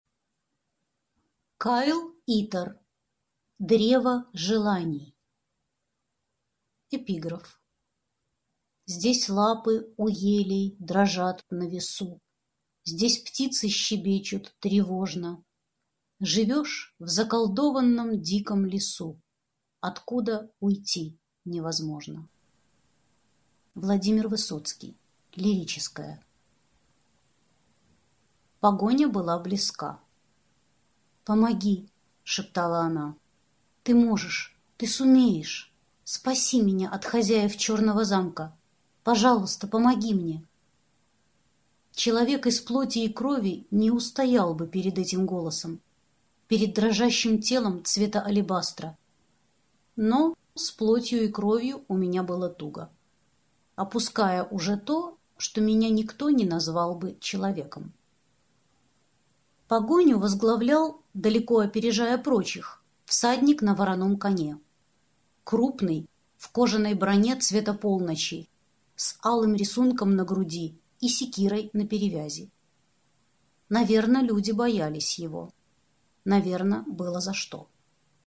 Аудиокнига Древо желаний | Библиотека аудиокниг